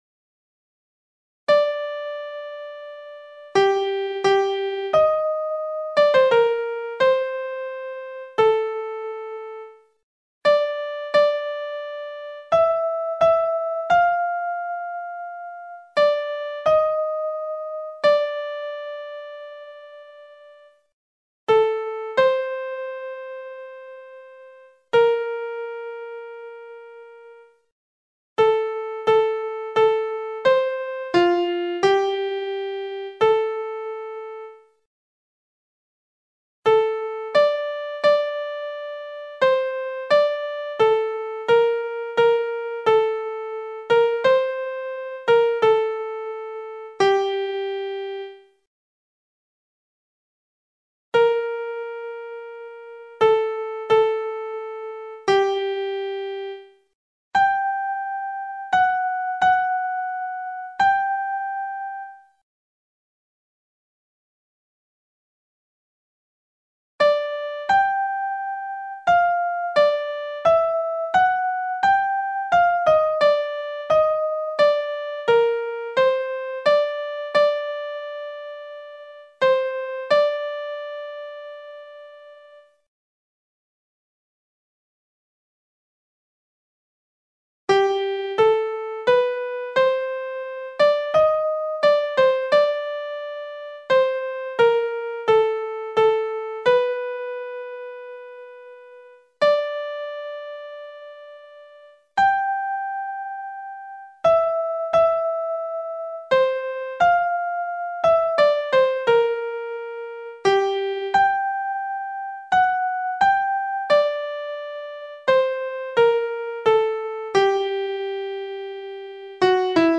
MIDI Sopranos